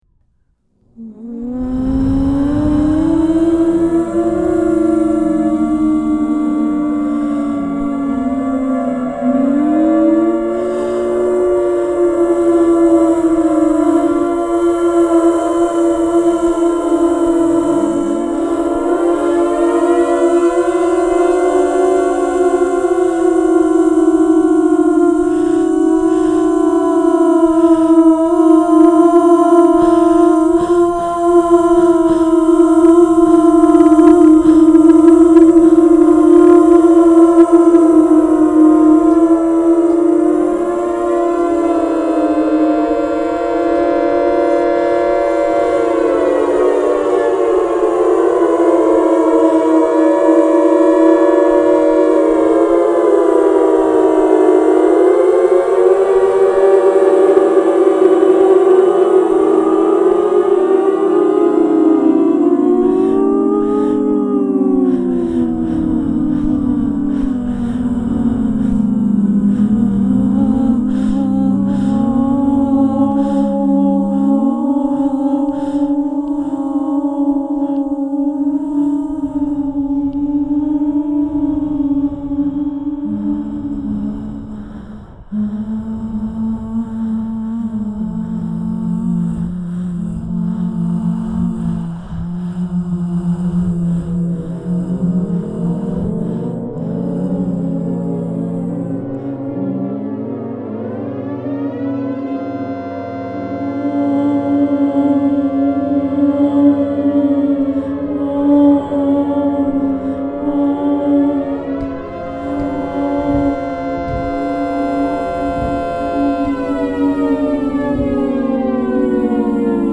The ventilation tubes that originally allowed the ship to “breathe” by bringing fresh air to the body of the ship now are used as resonators to amplify speakers, which emit processed recordings of female voices sighing, vocalizing in seductive/sexual ways, and mimicking sirens, combined with sampled land- and sea-siren and foghorn sounds, and the live sounds of the ship's motion. The sounds are constantly changing and are triggered by (and incorporate) the moaning and screaming sounds generated by the rocking of the boat against its entrance ramp and ropes. The sounds are spatialized throughout the ship, sometimes creating the beating patterns used in acoustic weapons to create confusion and dizziness, sometimes overwhelming the deck with a wash of soft vocal music.
Sirens mockup.mp3